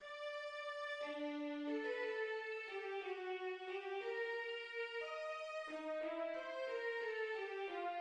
The Trio's theme in G major has hints of G minor: